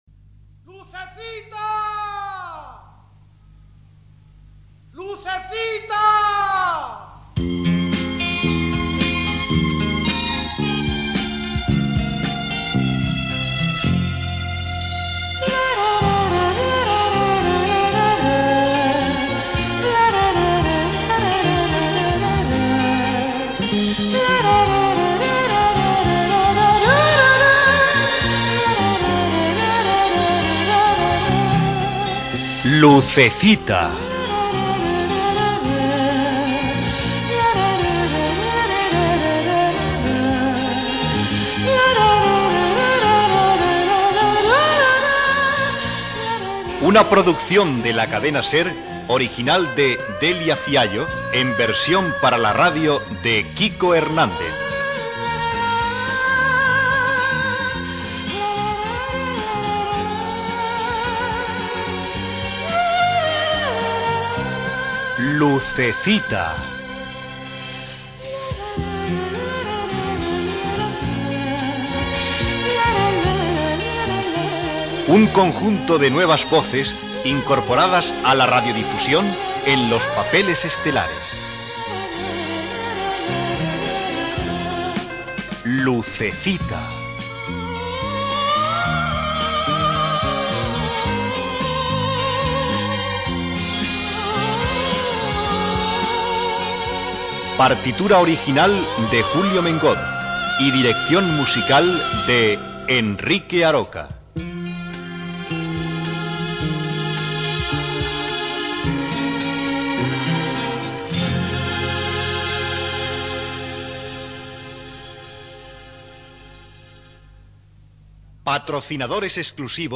Careta del serial radiofònic, publicitat, nou fascicle en fotonovel·la, el narrador explica la situació del dia del casament.
Ficció